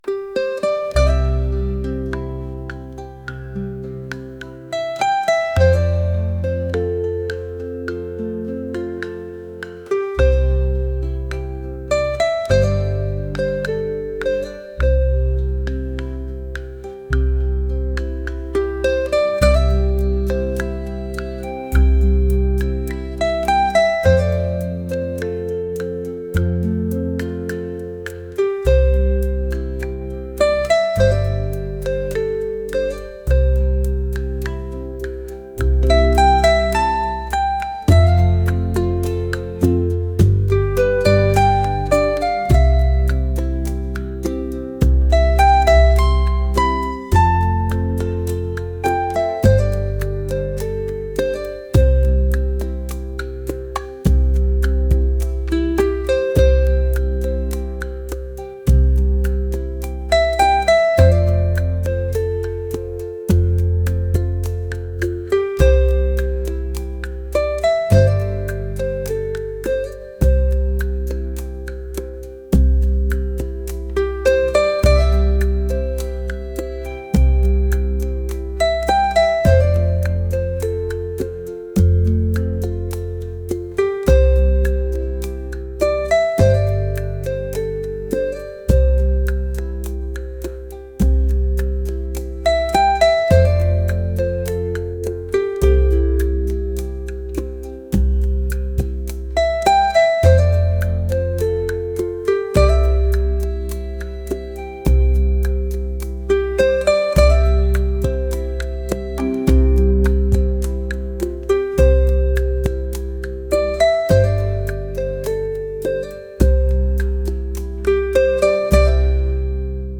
world | traditional